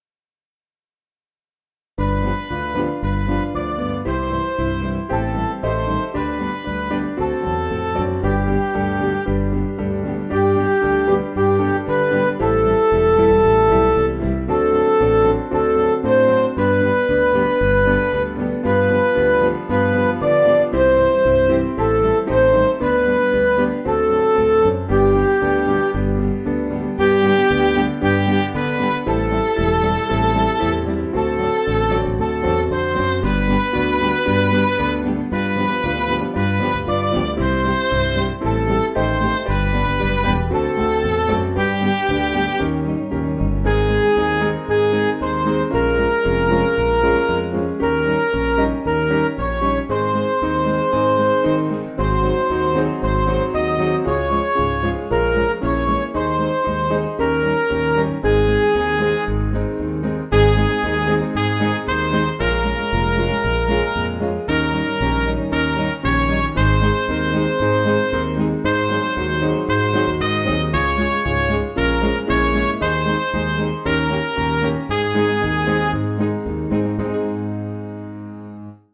Simple Piano
Midi